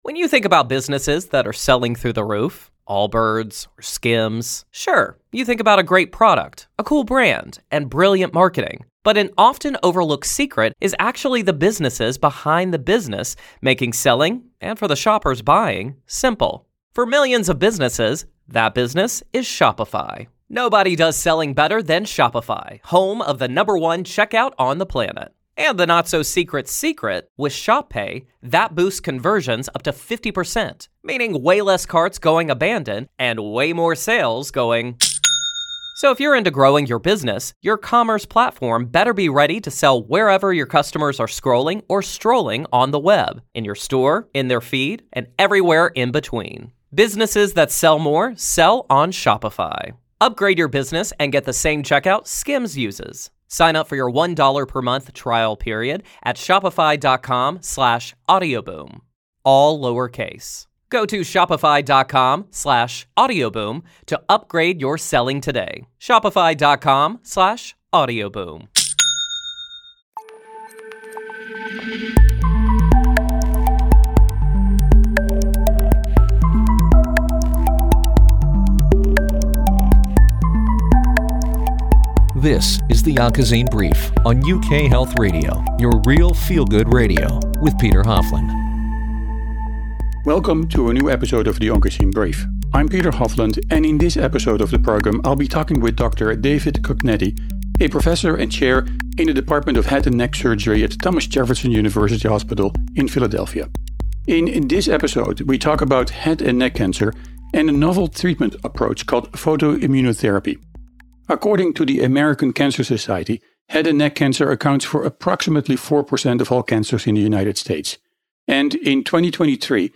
interview and discussion program